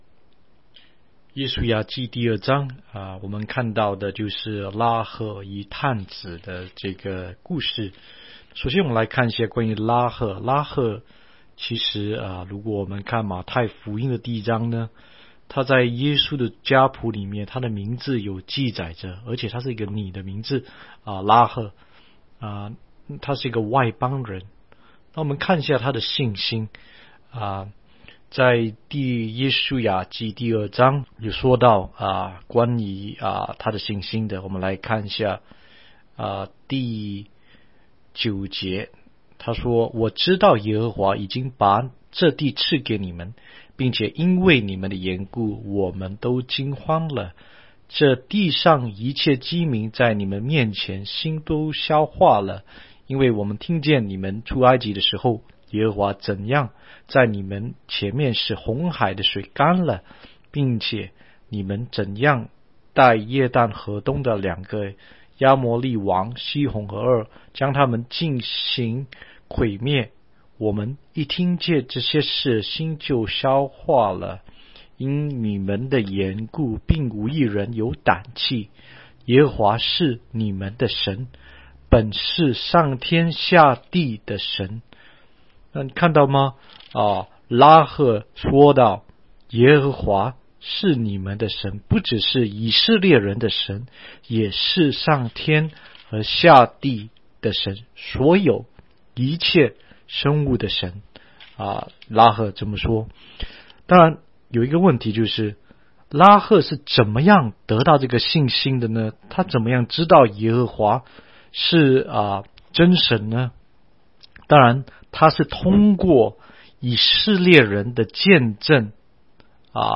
16街讲道录音 - 每日读经-《约书亚记》2章